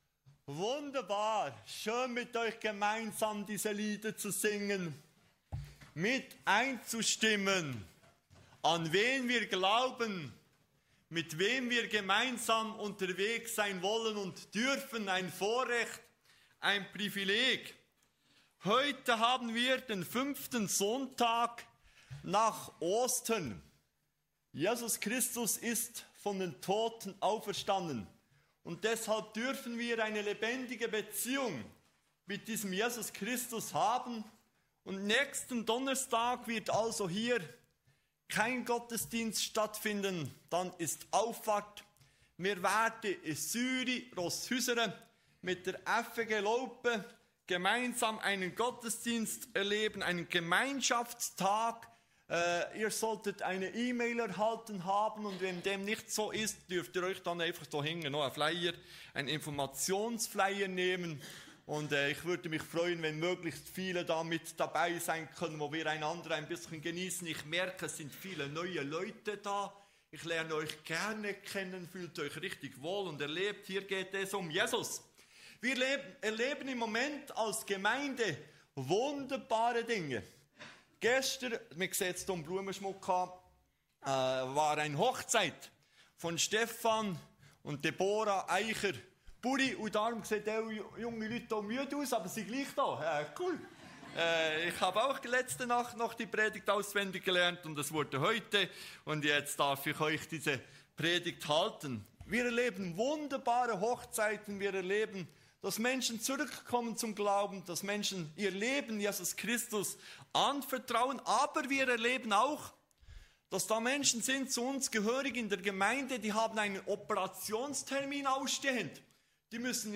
In dieser besonderen Folge nehmen wir dich mit hinein in eine inspirierende Predigt, die Mut macht und das Herz berührt. Wir entdecken, wie Gottes unsichtbare Hilfe näher ist, als wir oft denken, und wie Gebet unsere Perspektive verändern kann.
Kategorie: Gottesdienst